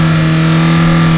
1 channel
MOTOR10.mp3